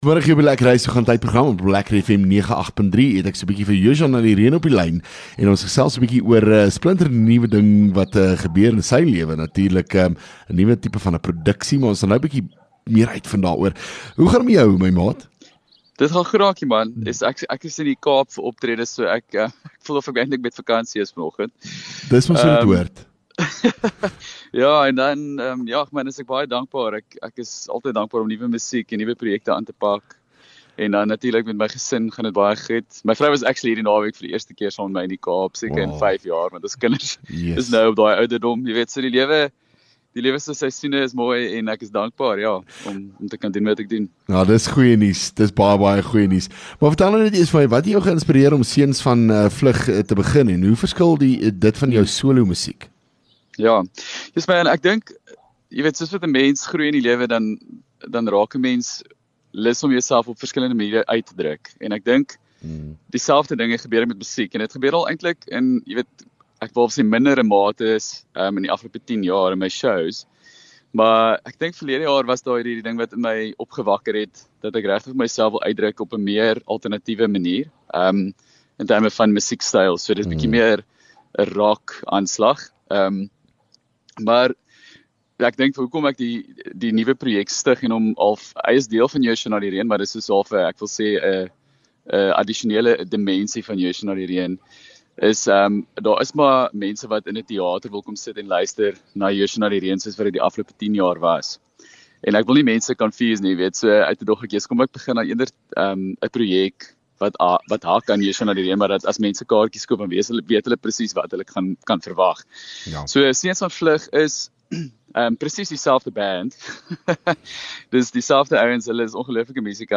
LEKKER FM | Onderhoude 11 Mar Seuns Van Vlug